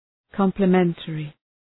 Προφορά
{,kɒmplə’mentərı}